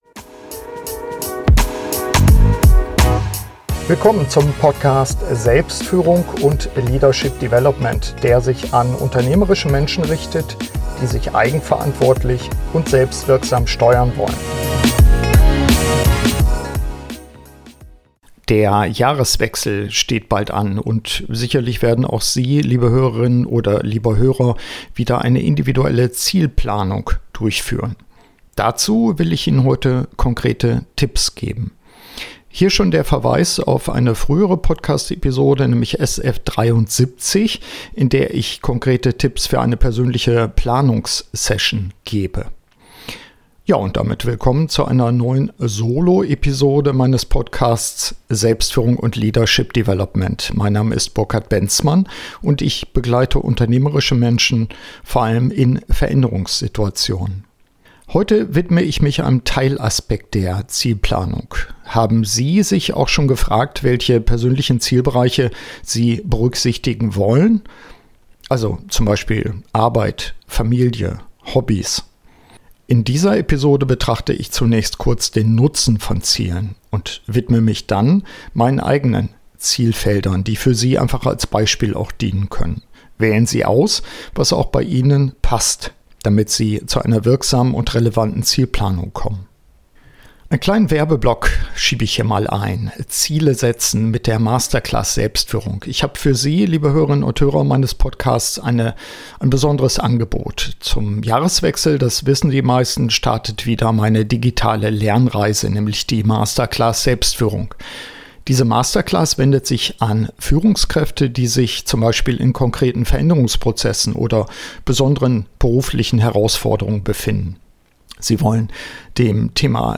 Dazu will ich Ihnen in dieser Soloepisode meines Podcasts konkrete Tipps geben.